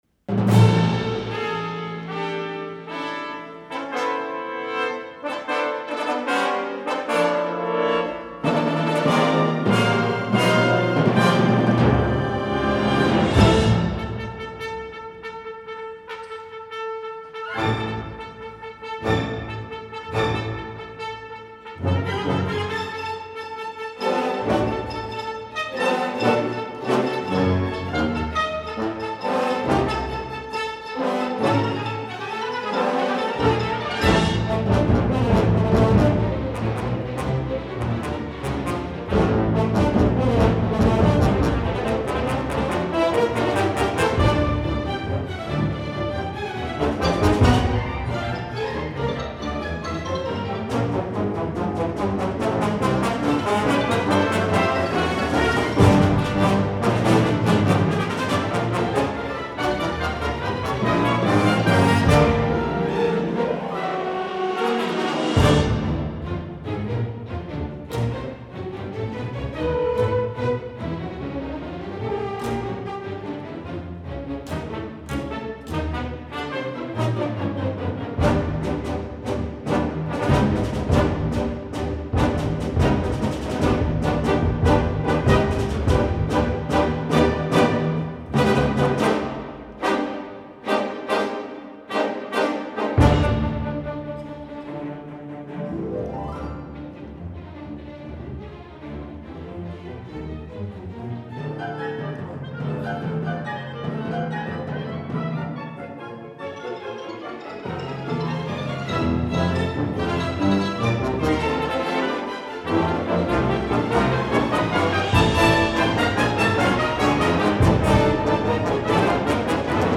motif, based on the Morse code for the word